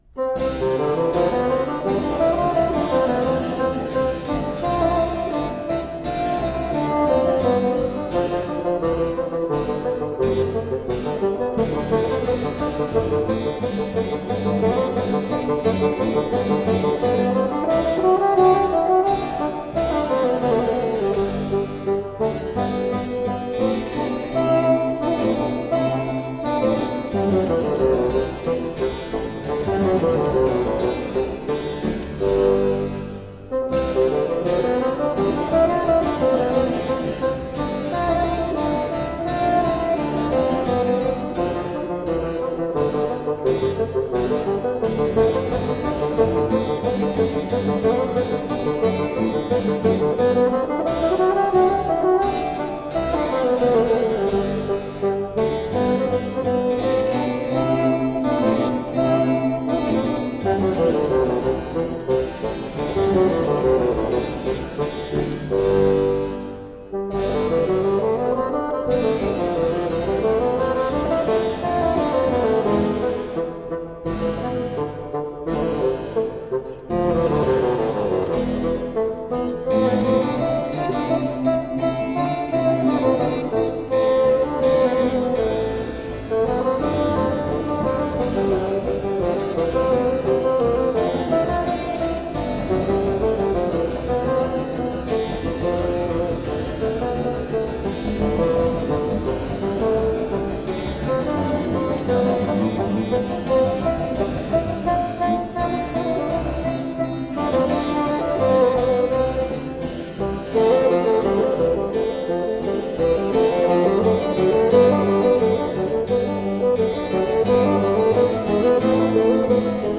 Buoyant baroque bassoon.
bright, warm tones